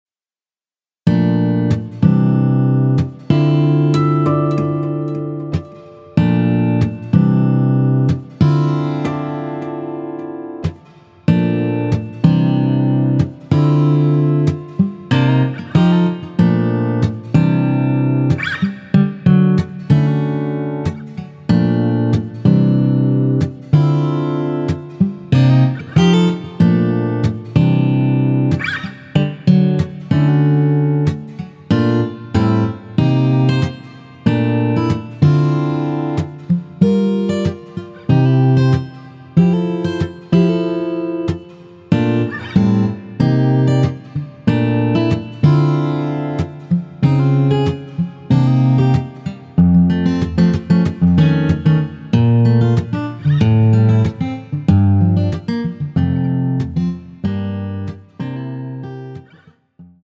Dm
앞부분30초, 뒷부분30초씩 편집해서 올려 드리고 있습니다.
중간에 음이 끈어지고 다시 나오는 이유는